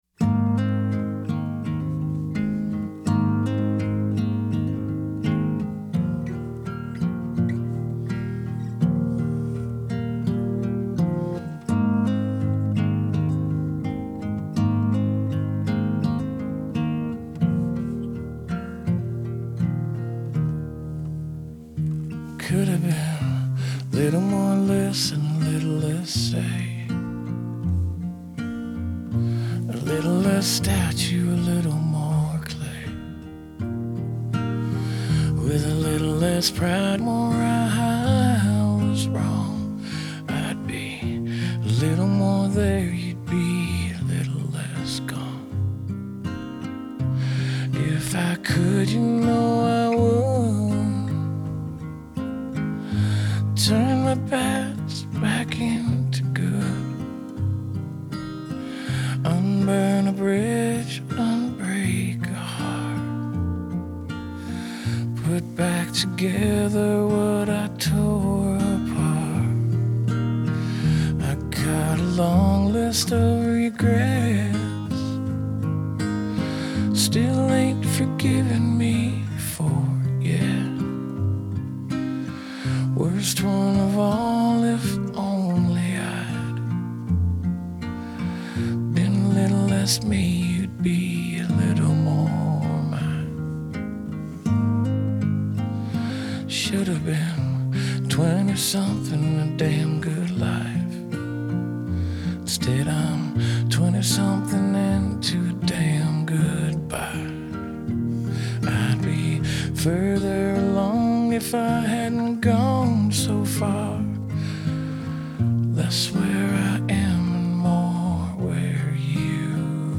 Genre : Country